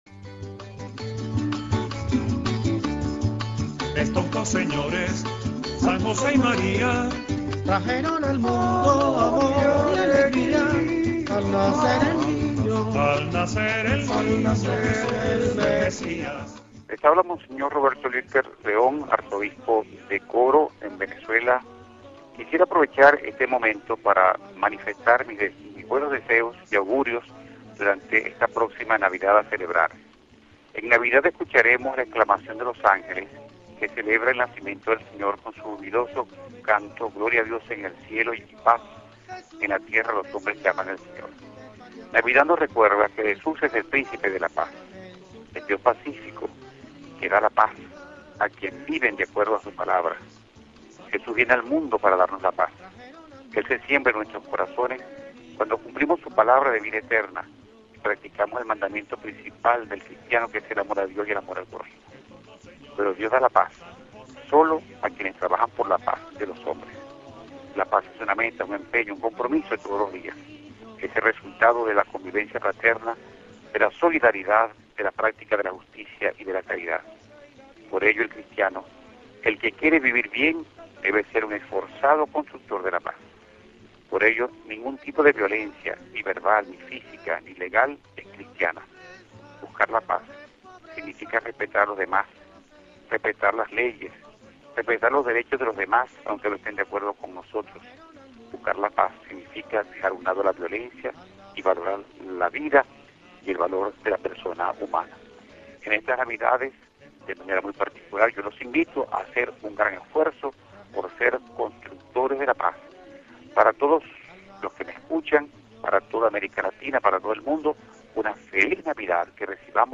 Cuando estamos celebrando este período navideño los obispos, los pastores de América Latina desde sus países se dirigen a todos nuestros oyentes con un mensaje de Navidad.